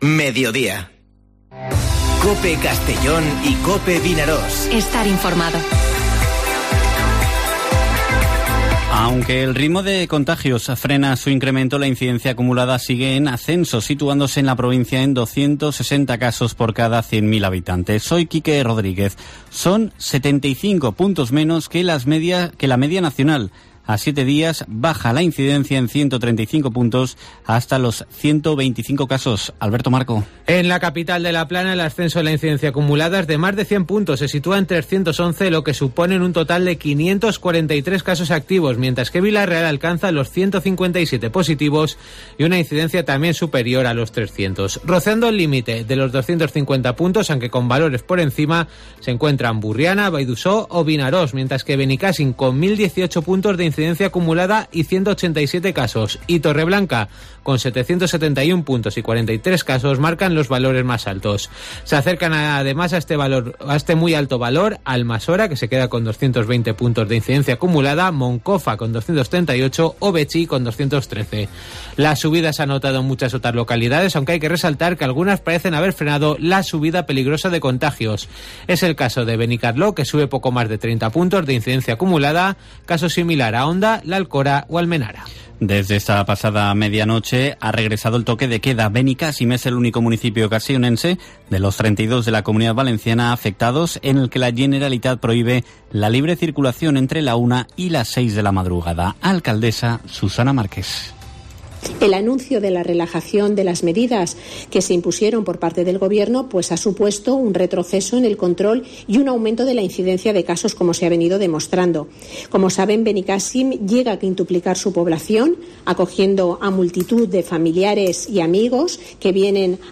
Informativo Mediodía COPE en la provincia de Castellón (13/07/2021)